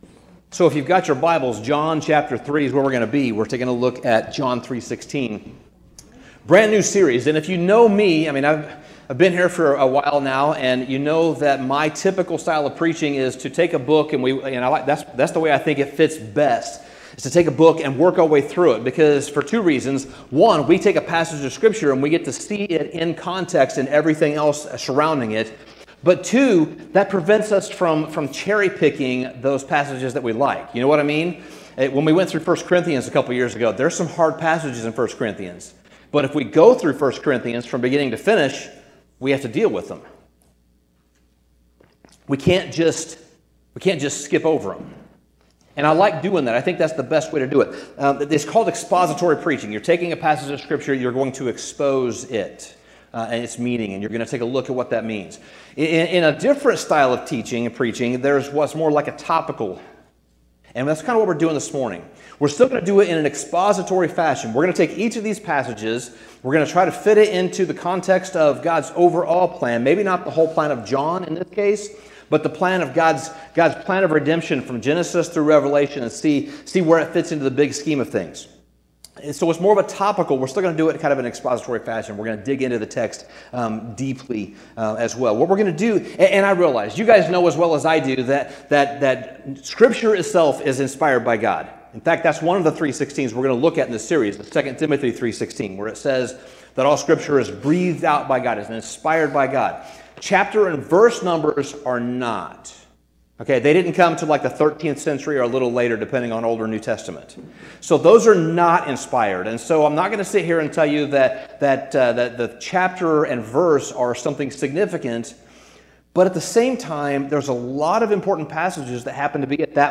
Sermon Summary The first 3:16 we explore is probably the most famous verse of the entire Bible: John 3:16.